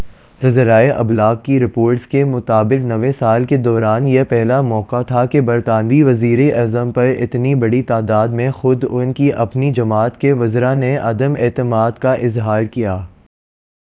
Spoofed_TTS/Speaker_14/259.wav · CSALT/deepfake_detection_dataset_urdu at main